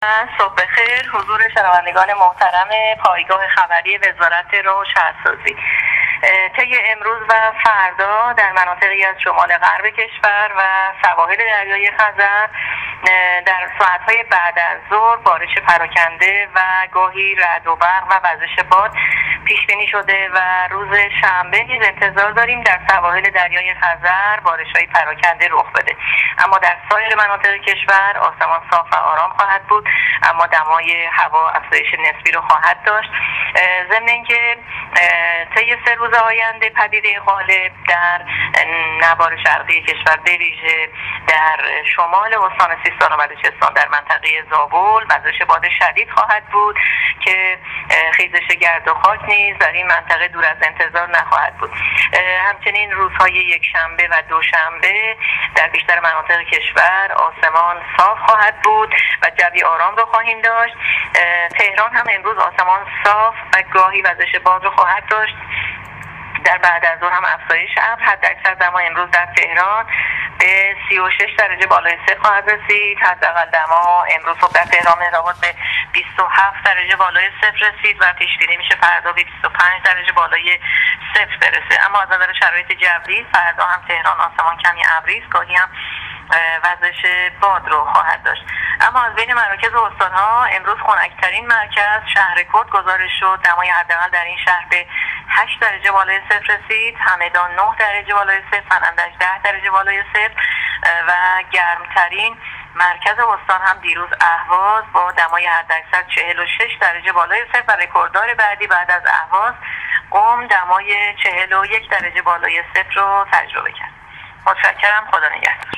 گزارش رادیو اینترنتی از آخرین وضعیت آب و هوای هشتم خرداد: